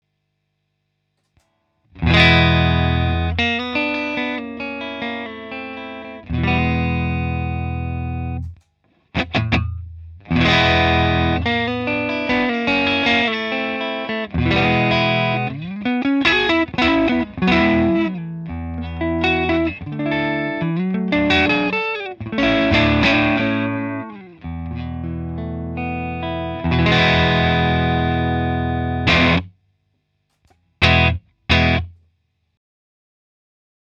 “Edge of breakup” neck single coil